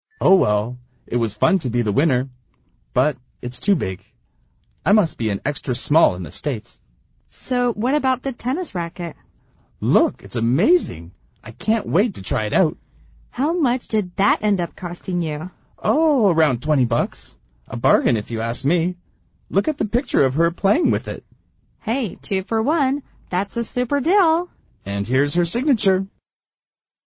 美语会话实录第144期(MP3+文本):That's a super deal!